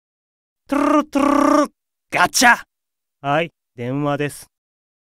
File:Leviathan Call Notification Voice.ogg
Leviathan_Call_Notification_Voice.ogg